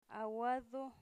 Phonological Representation a'wado